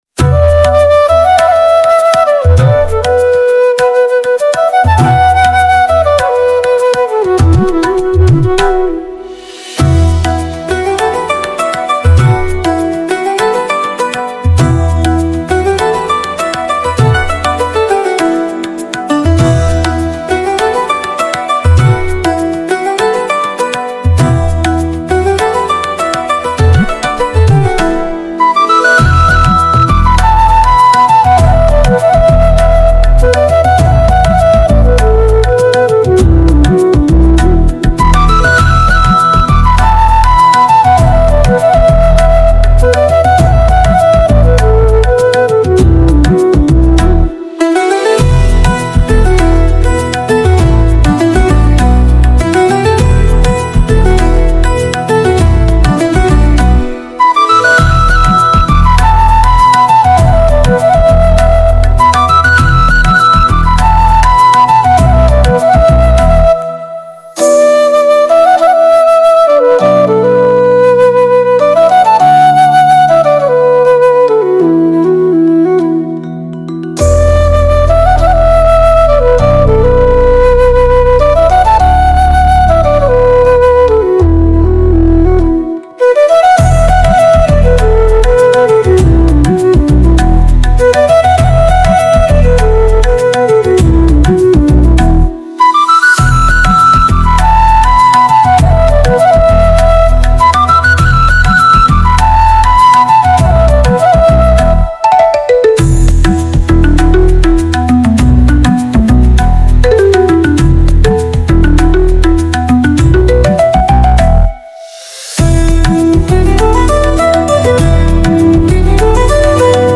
भारतीय संगीत चल रहा है